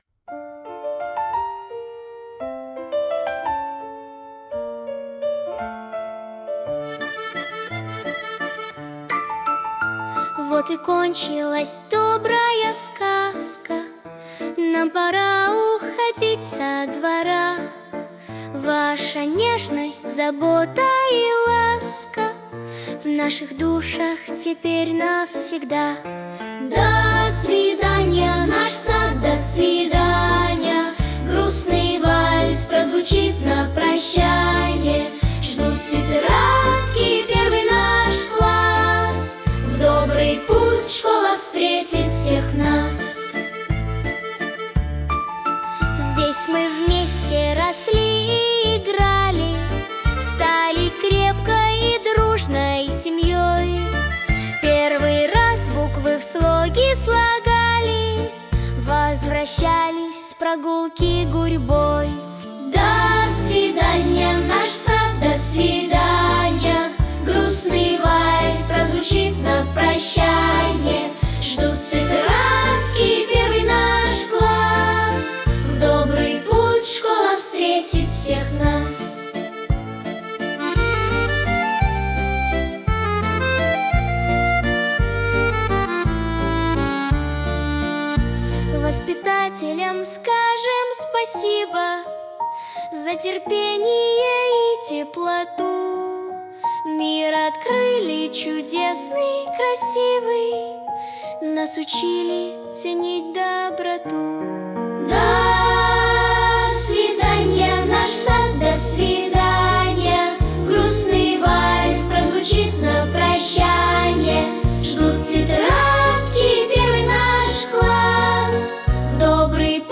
• Качество: Хорошее
• Жанр: Детские песни
прощальная, вальс, прощальный вальс